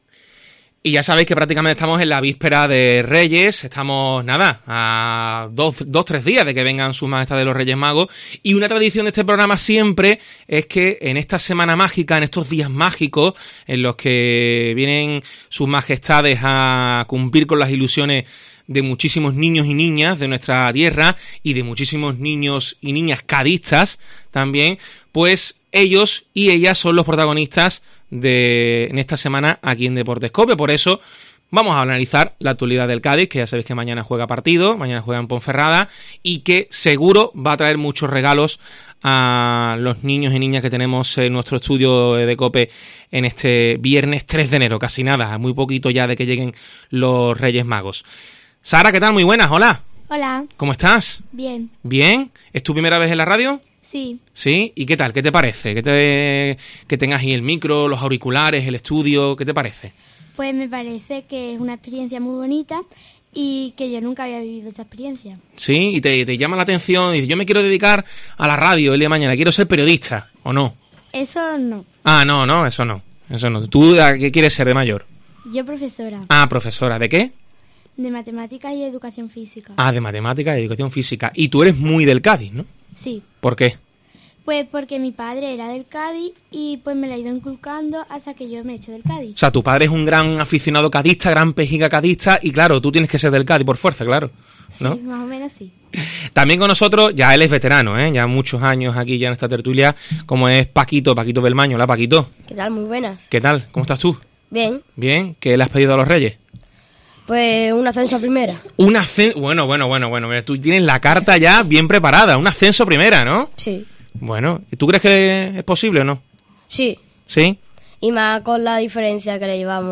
AUDIO: No te pierdas la tradicional tertulia con niños analizando toda la actualidad del Cádiz CF